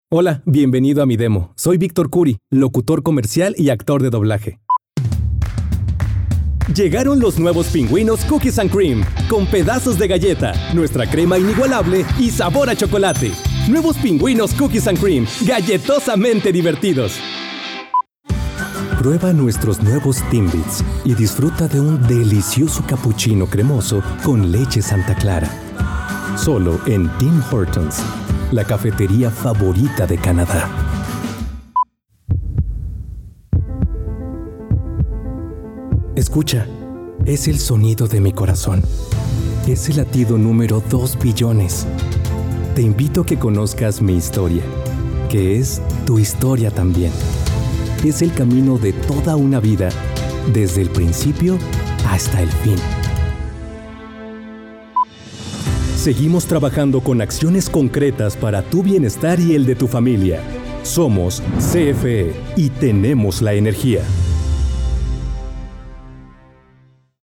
西班牙语男声
低沉|激情激昂|大气浑厚磁性|沉稳|娓娓道来|科技感|积极向上|时尚活力|神秘性感|调性走心|感人煽情|素人